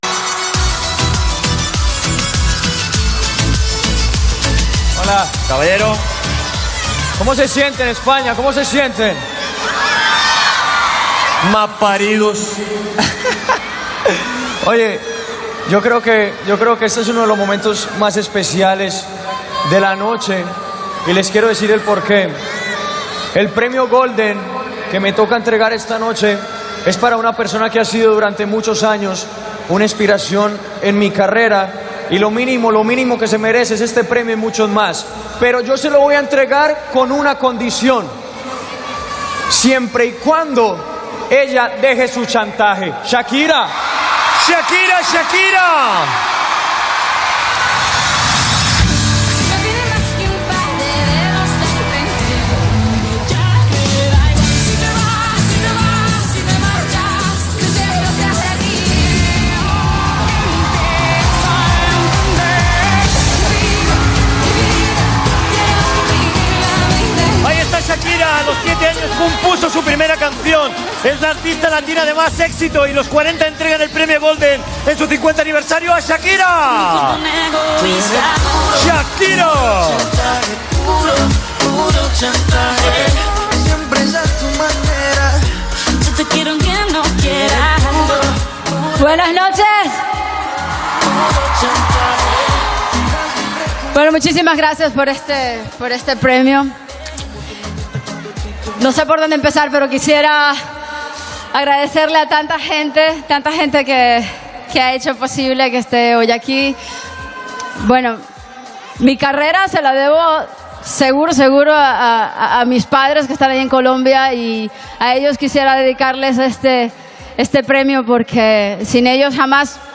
Transmissió des del Palau Sant Jordi de Barcelona de la gala. El cantant Maluma (Juan Luis Londoño) lliura el premi Golden a la cantant Shakira ( Shakira Isabel Mebarak ) i paraules seves d'agraïment.